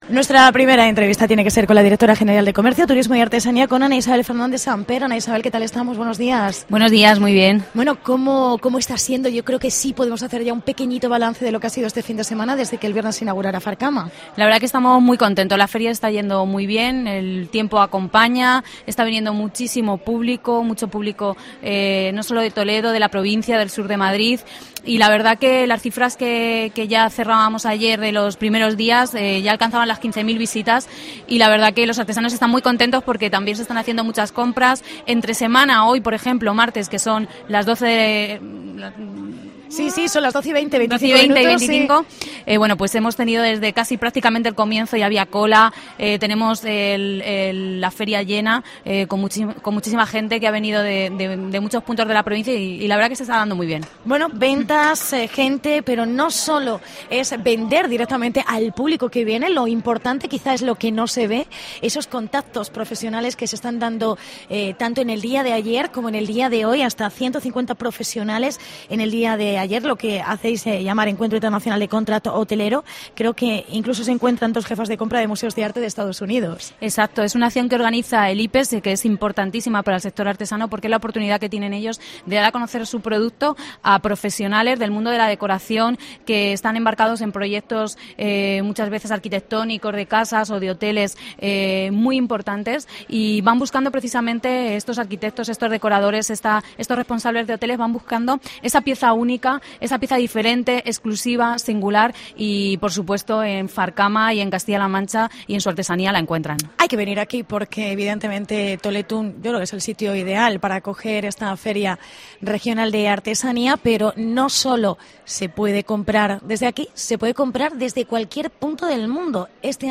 Entrevista Ana Isabel Fernández Samper. Directora General de Comercio, Turismo y Artesanía.